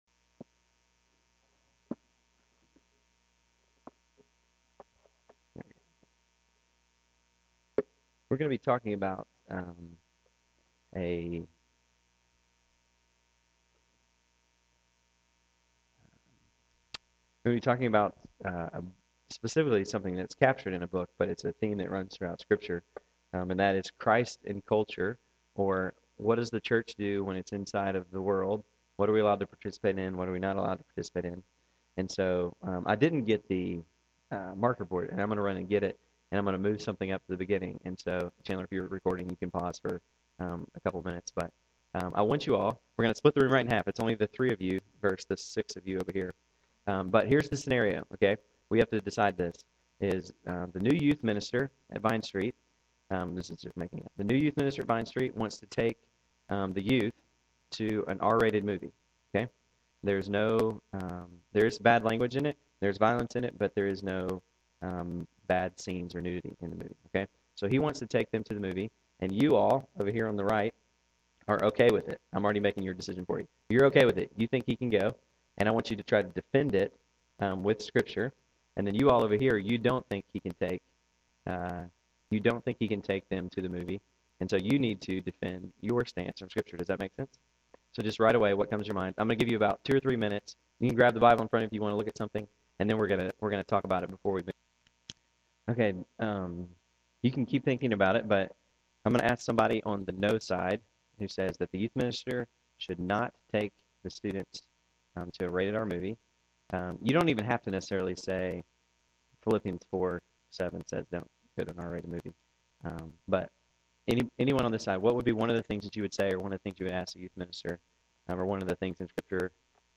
A handful of members gathered for a time of fellowship, songs and Bible Study.
Tonight’s study was interactive.